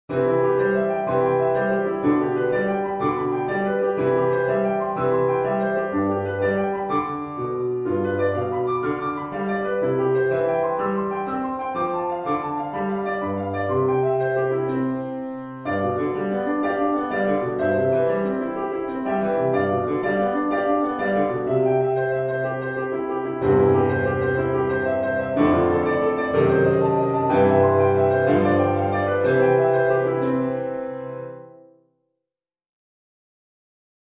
リピートは基本的に省略しています。